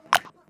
07_Clap_07_SP.wav